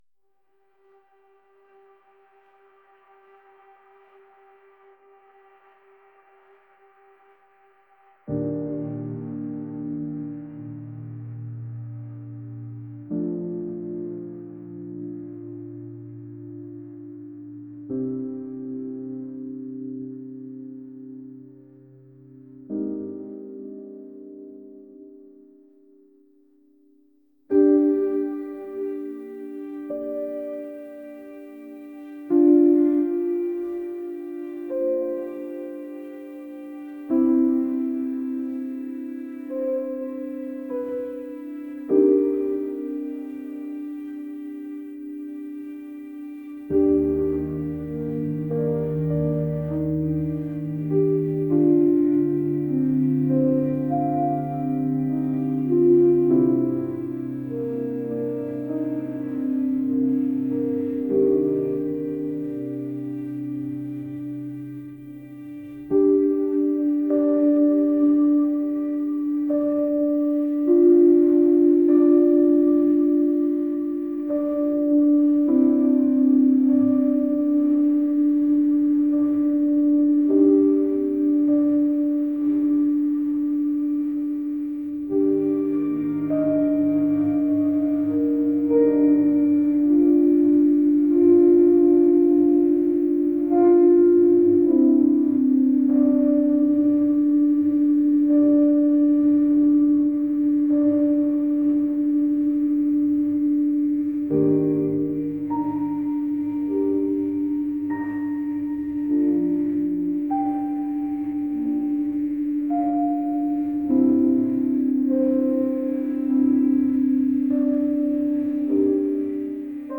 pop | atmospheric | ethereal